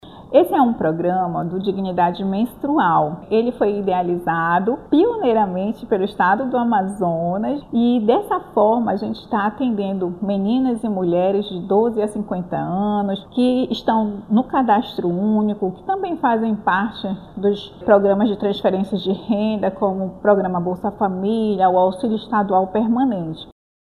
A iniciativa busca combater a pobreza menstrual no Amazonas. As unidades do projeto Prato Cheio na capital começaram a receber 200 kits do programa, que estão sendo entregues às frequentadoras que se encaixam nos critérios estabelecidos, como explica a secretária executiva adjunta da Secretaria de Estado da Assistência Social e Combate à Fome (Seas), Selma Melo.